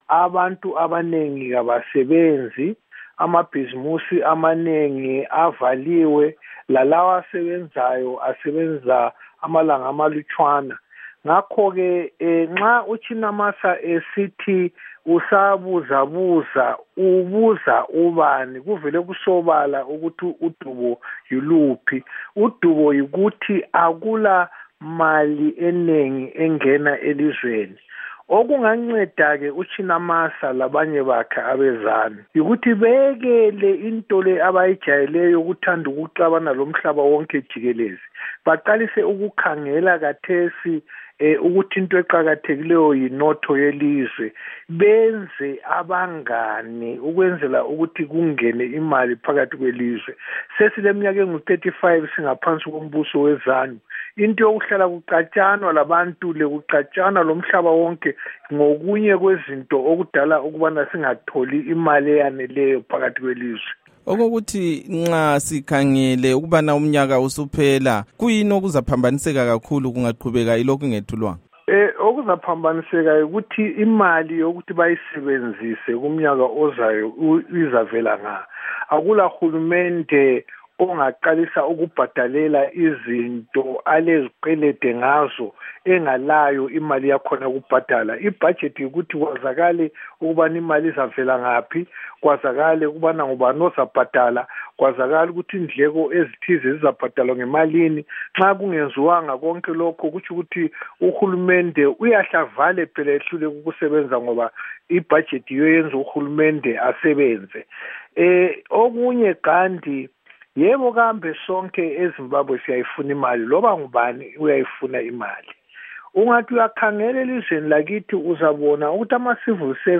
Ingxoxo loMnu. Paul Themba Nyathi